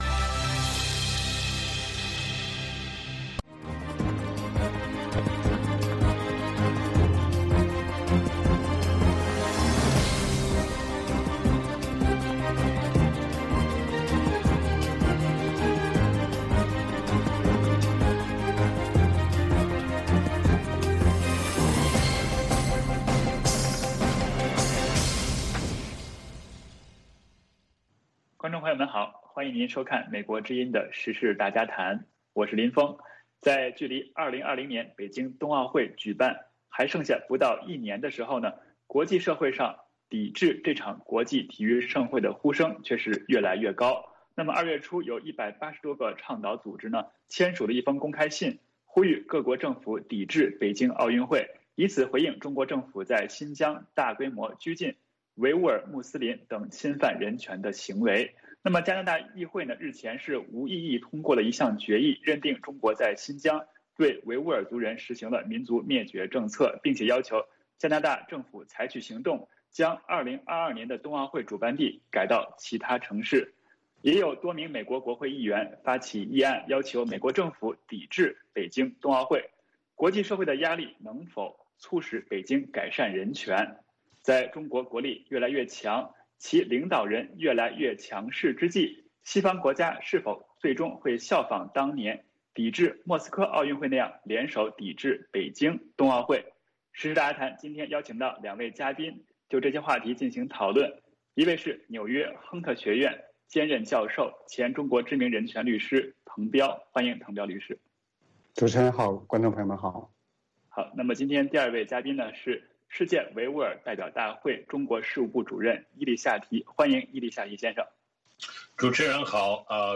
《时事大家谈》围绕重大事件、热点问题、区域冲突以及中国内政外交的重要方面，邀请专家和听众、观众进行现场对话和讨论，利用这个平台自由交换看法，探索事实。